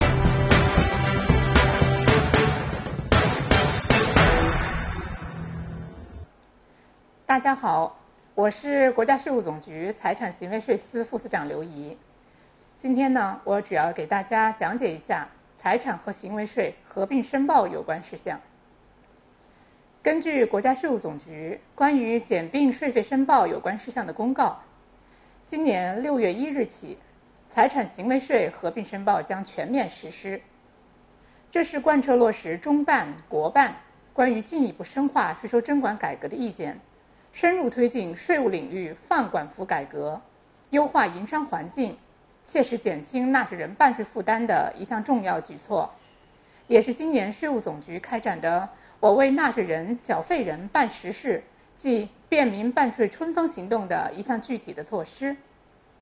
5月31日，聚焦6月1日起实施的财产和行为税合并申报，国家税务总局“税务讲堂”开讲。税务总局财产和行为税司副司长刘宜担任主讲，为纳税人解读新政策的意义和新申报表的填写方法。